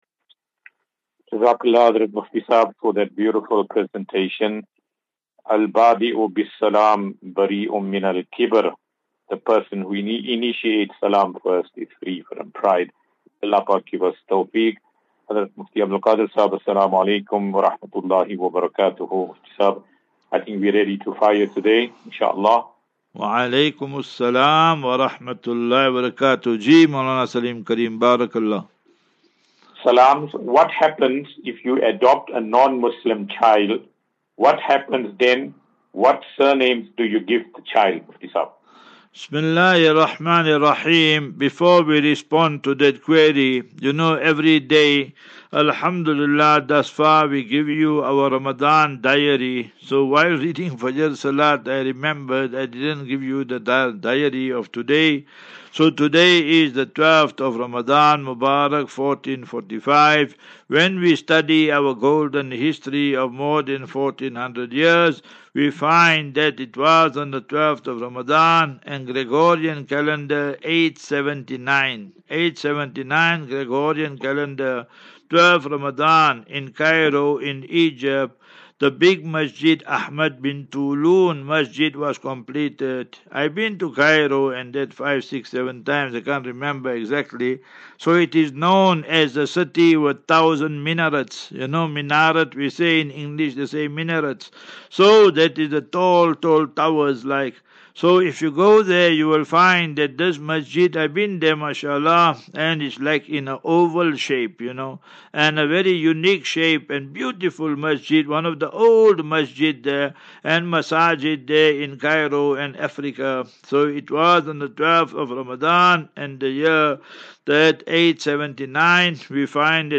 View Promo Continue Install As Safinatu Ilal Jannah Naseeha and Q and A 23 Mar 23 March 2024.